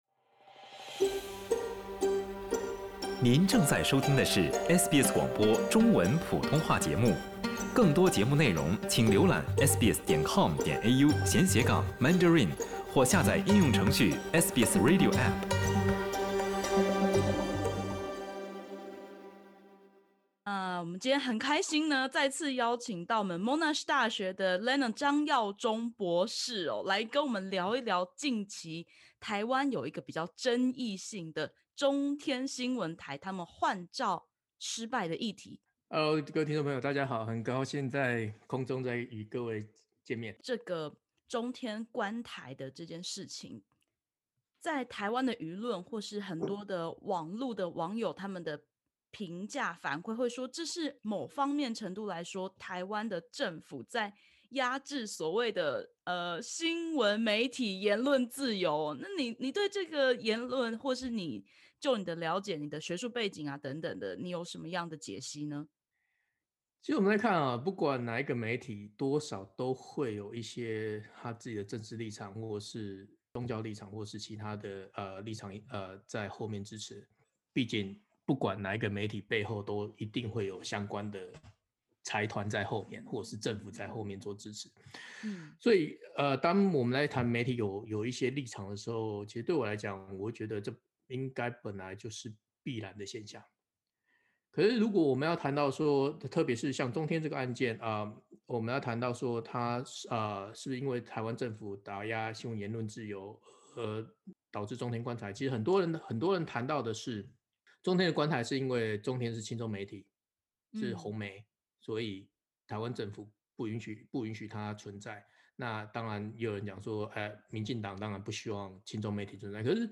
點擊首圖收聽完整採訪podcast。